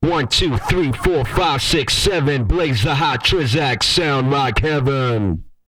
TB COUNTING.wav